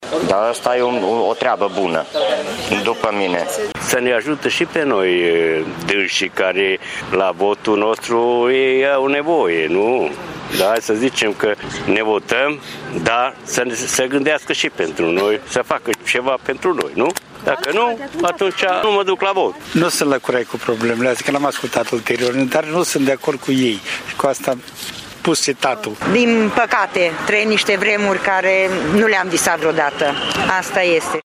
Părerile tîrgumureşenilor sunt împărţite. Unii spun că legea este bună, alţii că legea creează discriminare: